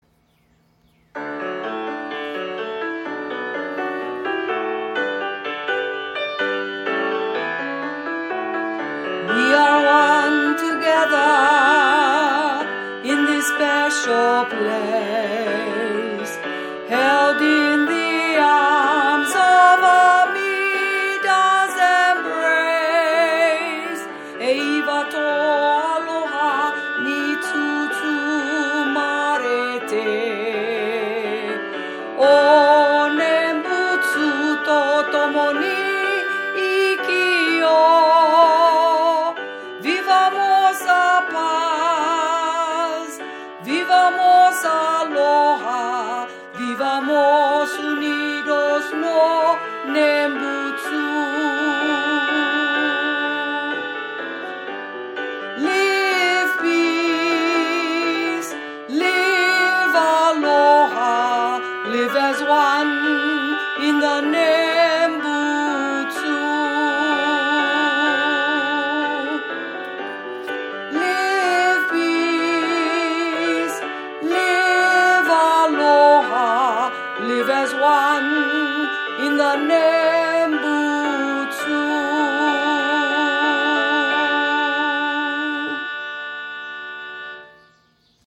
piano accompaniment
wbwc2027_melody_with_voice.mp3